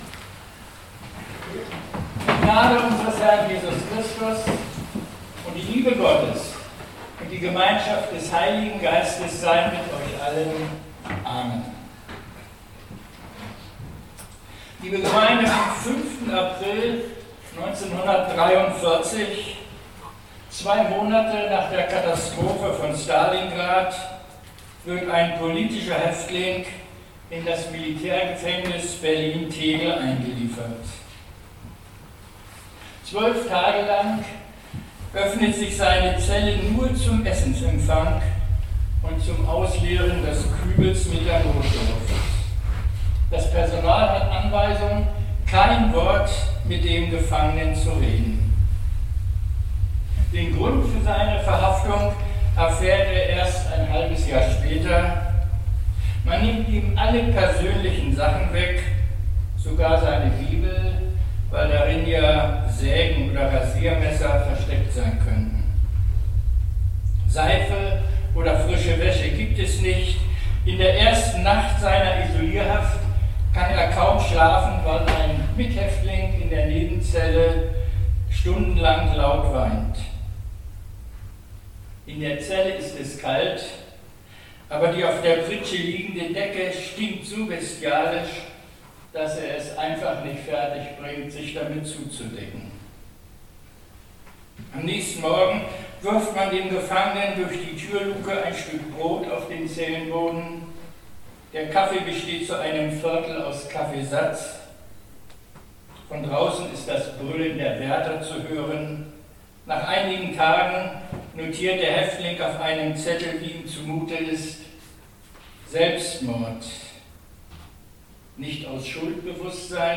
Gottesdienstpredigt - 27.04.2025 ~ Peter und Paul Gottesdienst-Podcast Podcast